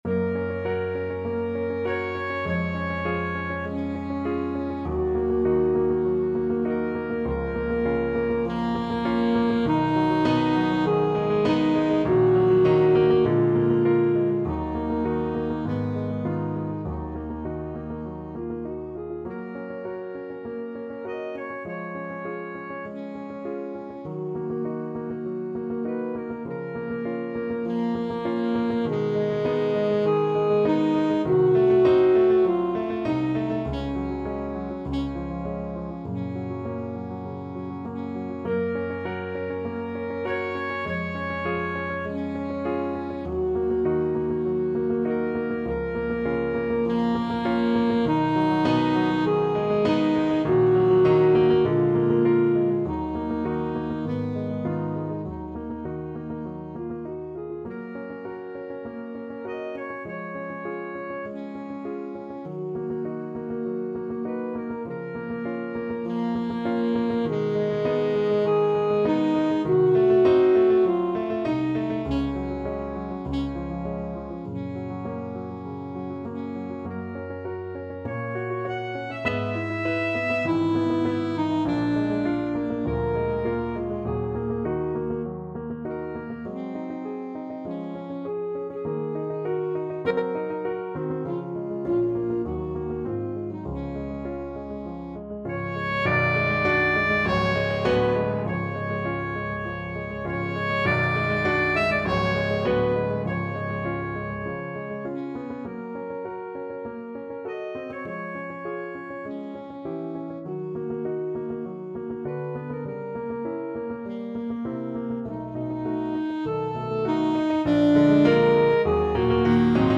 Classical Dvořák, Antonín Four Romanic Pieces, Op.75, No.1 Alto Saxophone version
Gb major (Sounding Pitch) Eb major (Alto Saxophone in Eb) (View more Gb major Music for Saxophone )
Allegro moderato (View more music marked Allegro)
Classical (View more Classical Saxophone Music)